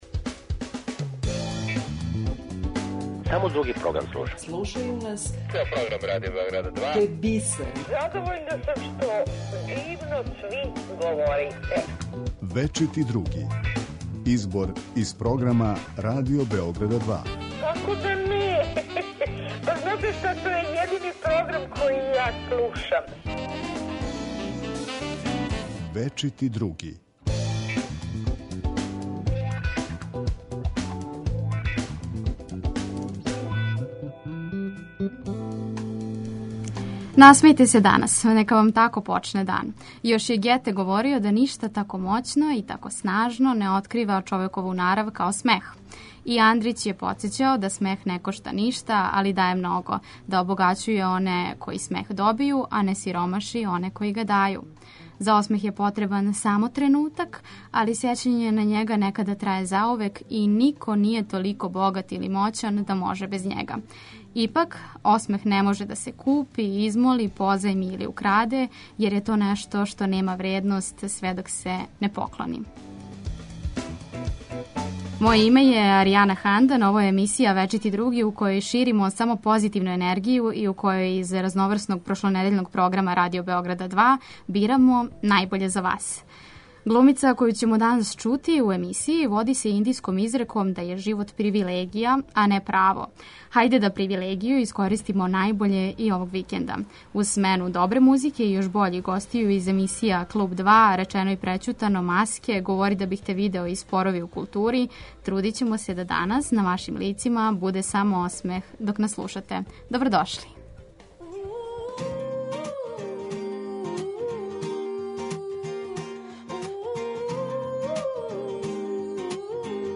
Пренећемо вам и део атмосфере са доделе признања Зелени и Црни лист , као и причу о младом сликару коме су одузели пејзаж.